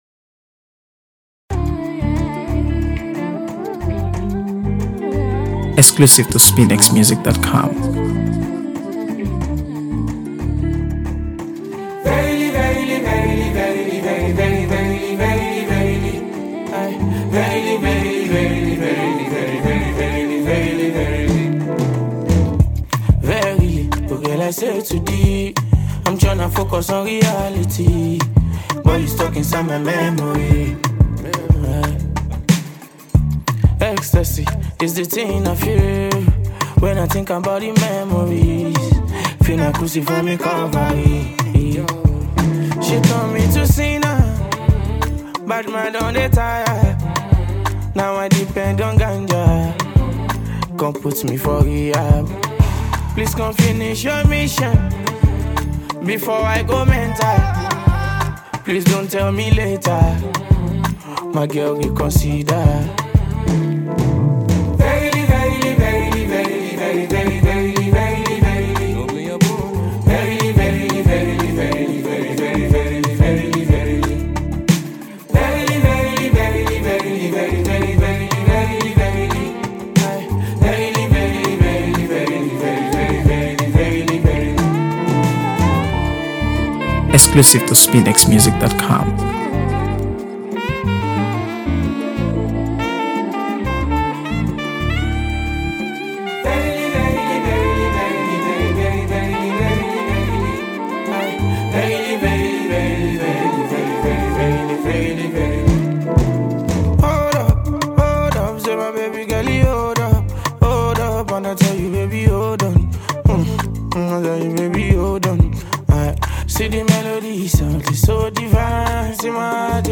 AfroBeats | AfroBeats songs
Acclaimed Nigerian singer